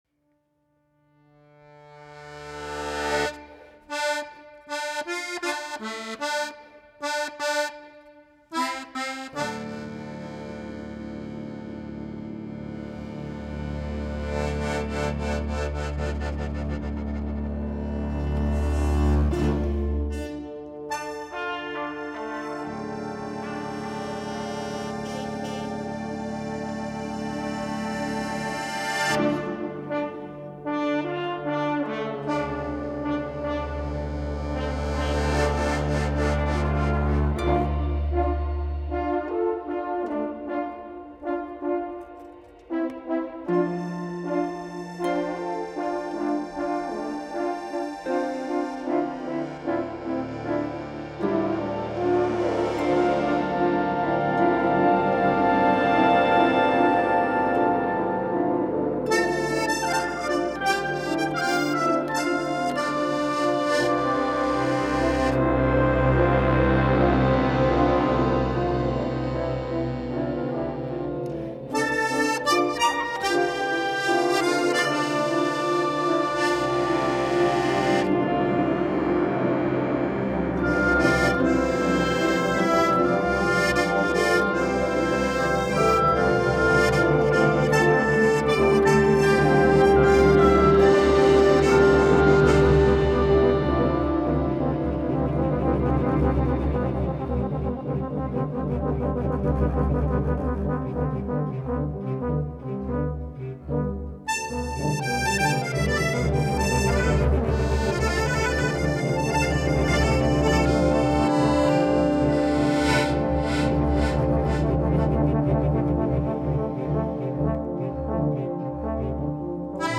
his concerto for accordion and wind ensemble